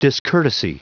Prononciation du mot discourtesy en anglais (fichier audio)
Prononciation du mot : discourtesy
discourtesy.wav